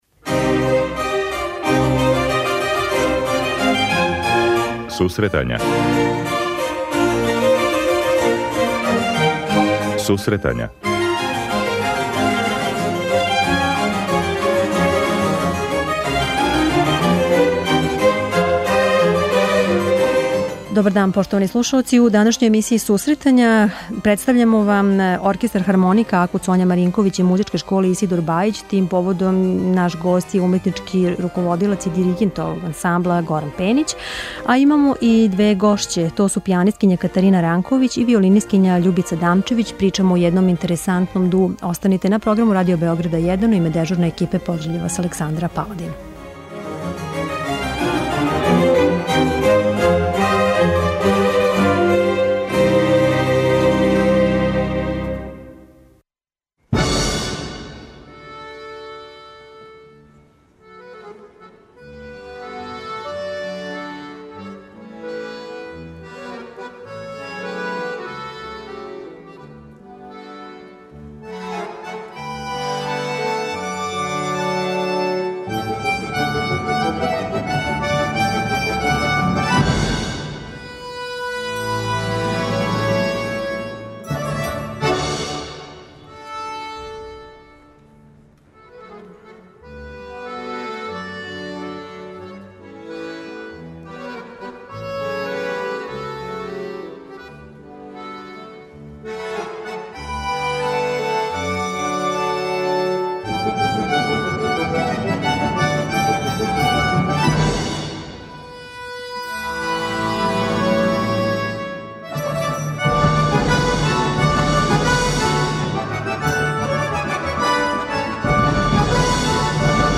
преузми : 50.44 MB Сусретања Autor: Музичка редакција Емисија за оне који воле уметничку музику.